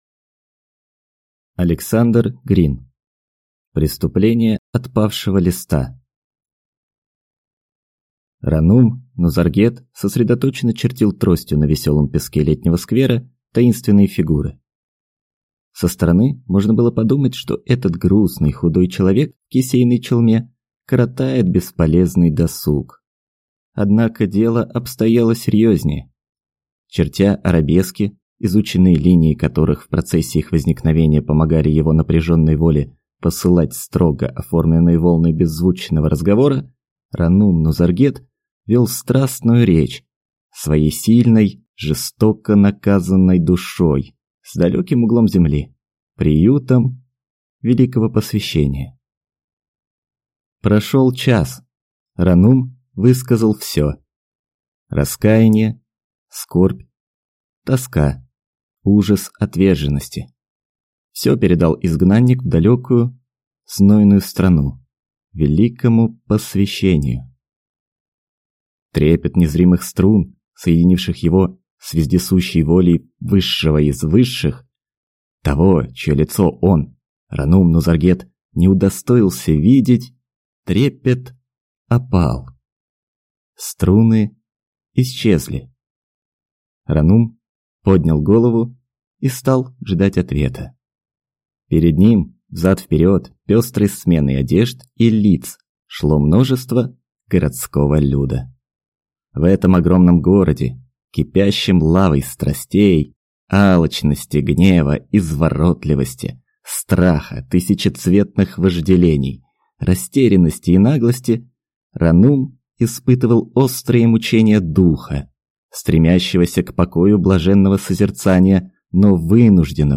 Аудиокнига Преступление Отпавшего Листа | Библиотека аудиокниг